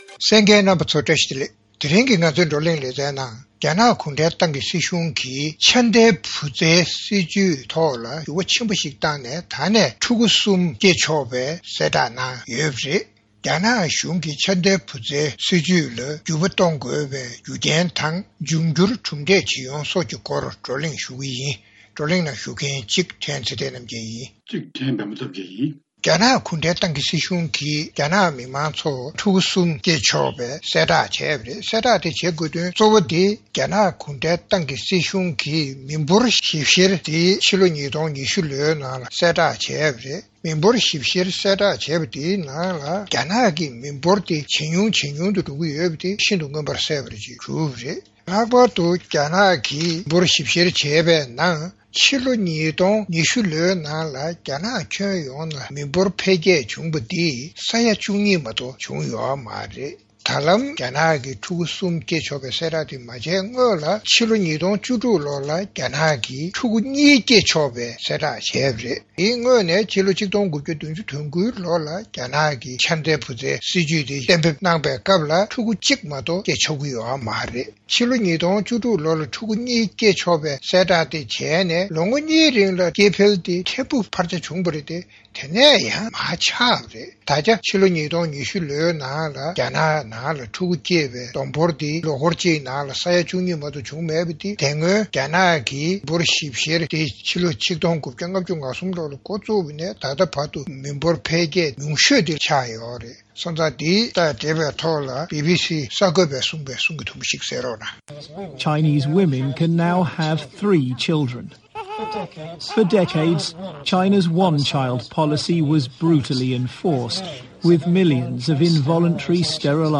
རྩོམ་སྒྲིག་པའི་གླེང་སྟེགས་ཞེས་པའི་ལེ་ཚན་ནང་།རྒྱ་ནག་གཞུང་གིས་འཆར་ལྡན་བུ་བཙའི་སྲིད་བྱུས་ལ་བསྒྱུར་བ་བཏང་སྟེ་ཕྲུག་གུ་གསུམ་སྐྱེ་ཆོག་པའི་གསལ་བསྒྲགས་བྱ་དགོས་དོན་དང་། གྲུབ་འབྲས་ཇི་ཡོང་སོགས་ཀྱི་སྐོར་ལ་བགྲོ་གླེང་གནང་བ་གསན་རོགས་ཞུ།།